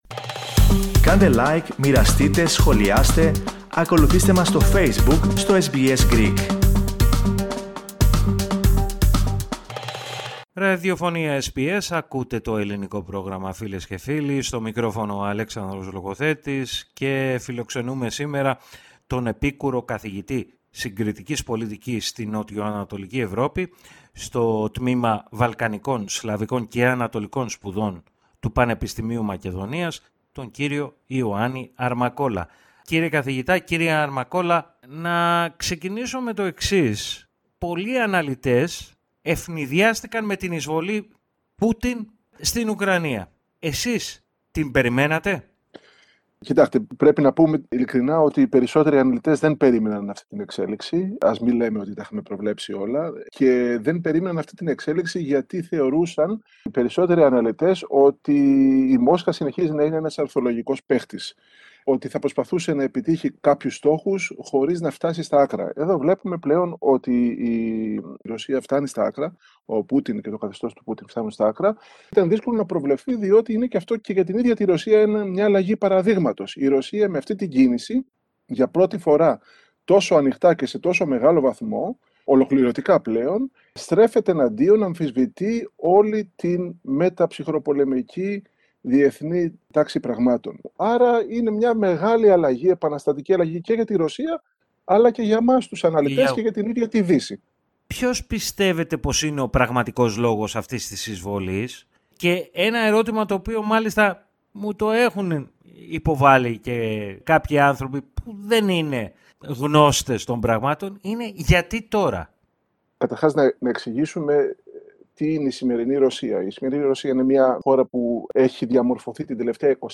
Για τον πόλεμο στην Ουκρανία, μίλησε στο Ελληνικό Πρόγραμμα της ραδιοφωνίας SBS